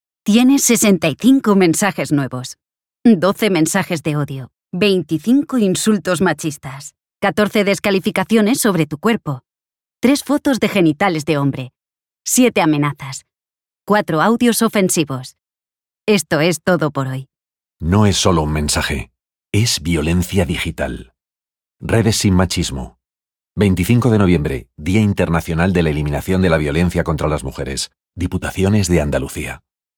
Cuña de radio 1 25N20